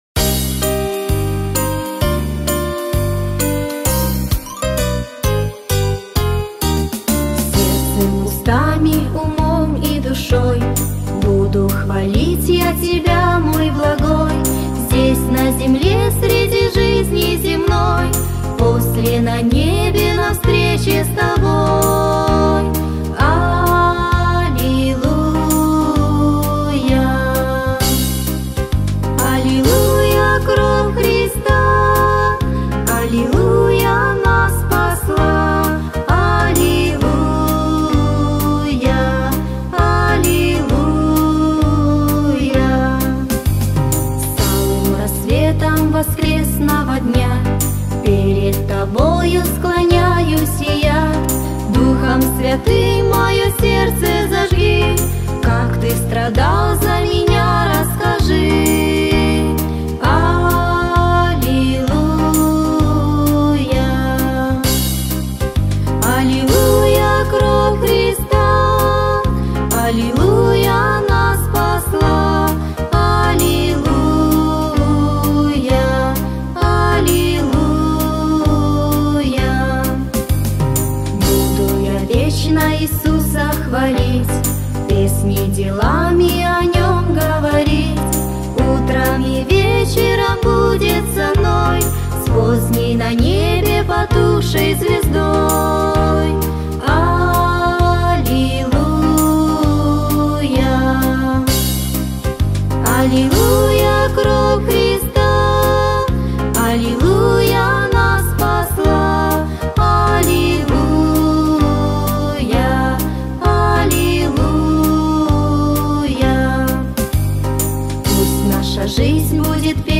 564 просмотра 177 прослушиваний 20 скачиваний BPM: 115